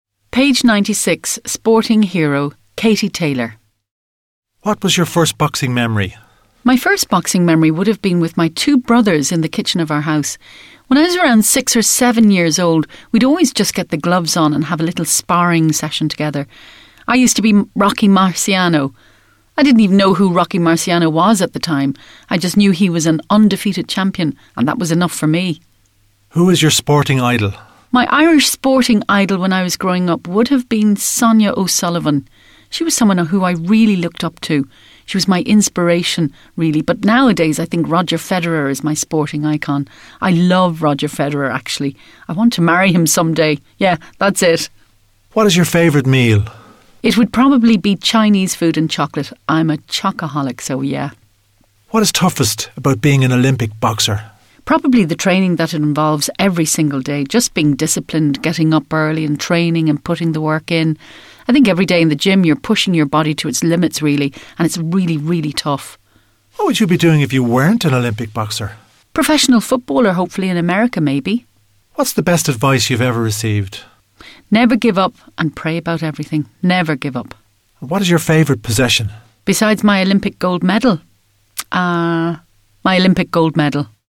Katie Taylor interiew (audio file)
Interview-with-Katie-Taylor.mp3